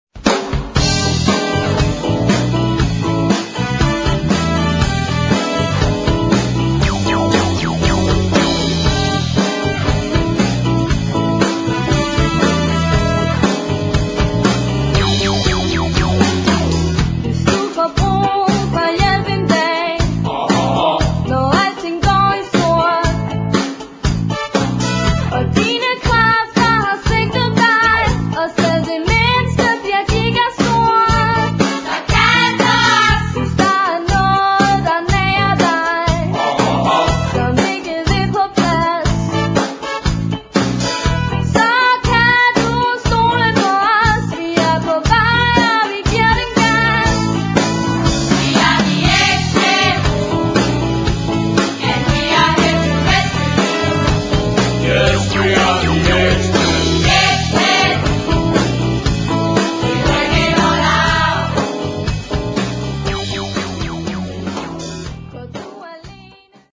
Der er både pop, rock og rumba.